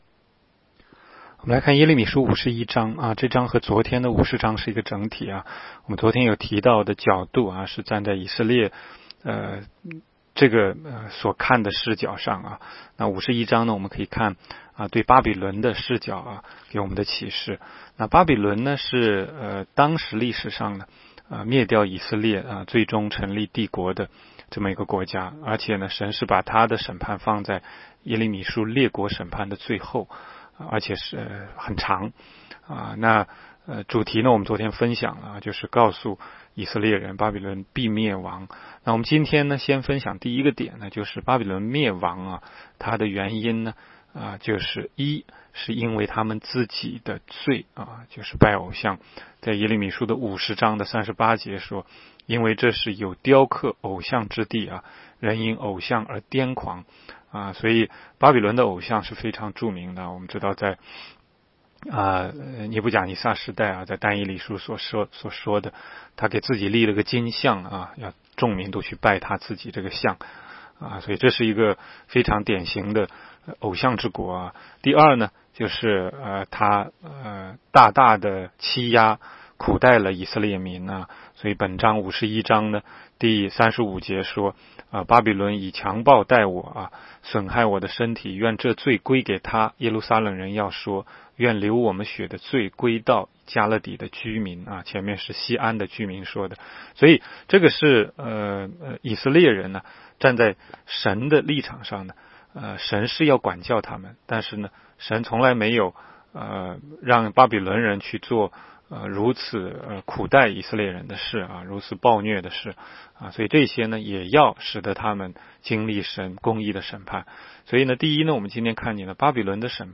16街讲道录音 - 每日读经 -《耶利米书》51章